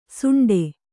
♪ suṇḍe